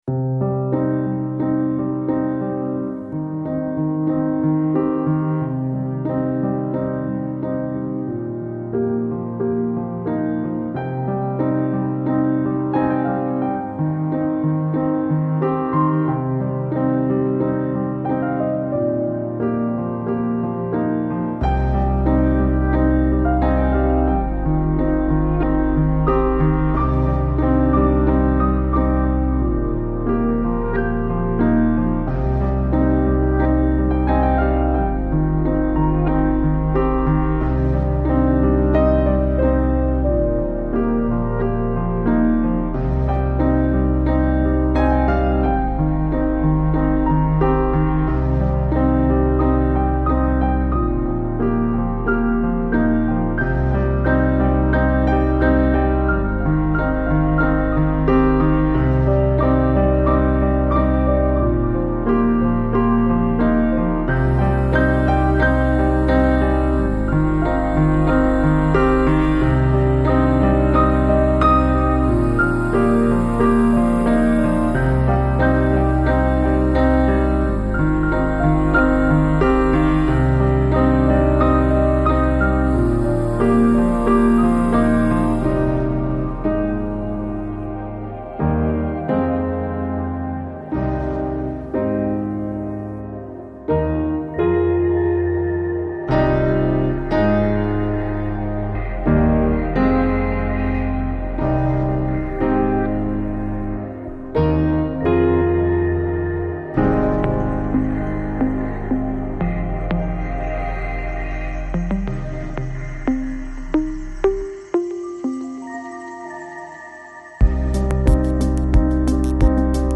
Жанр: Chillout, Lounge, Ambient, Downtempo, House